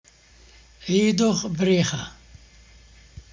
Merry Christmas greeting to a man.